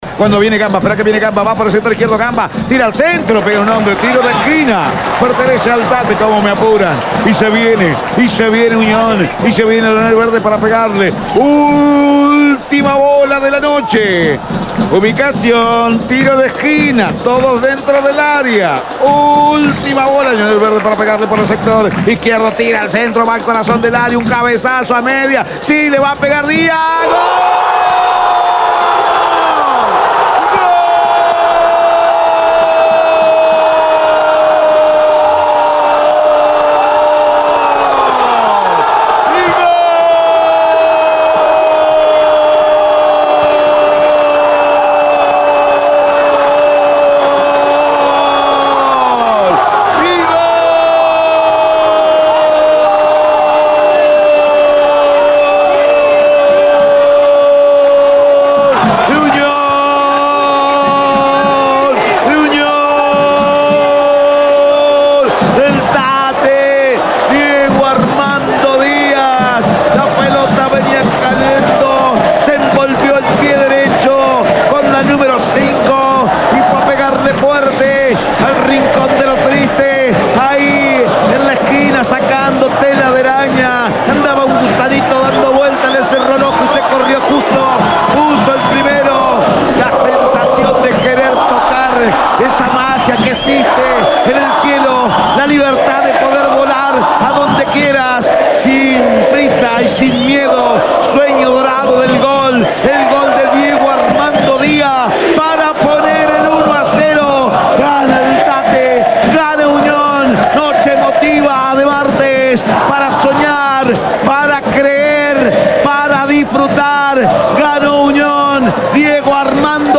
EL GOL DE UNIÓN, EN EL RELATO